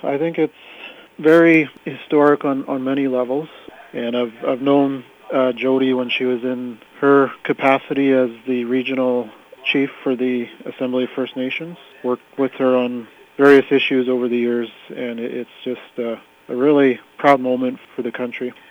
Here’s Lower Kootenay Band Chief, Jason Louie.